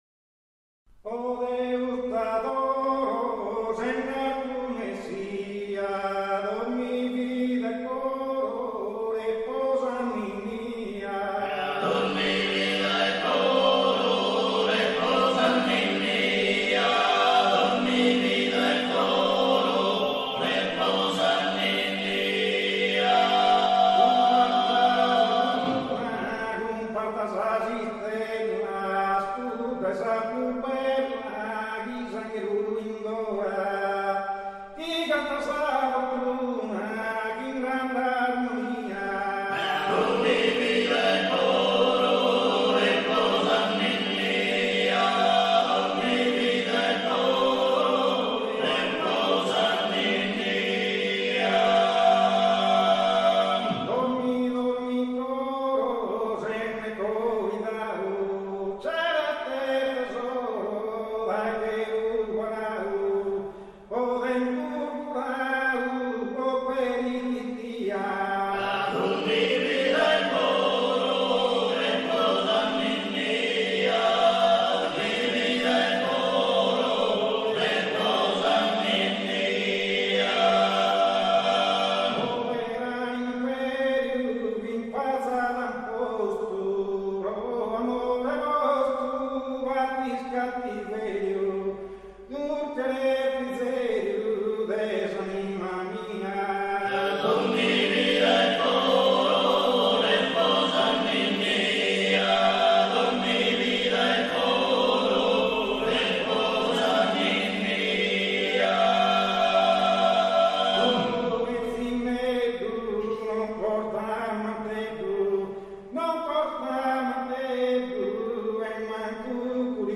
Ninnieddu - Chiesa San Gavino - Gavoi
Cantu de nadale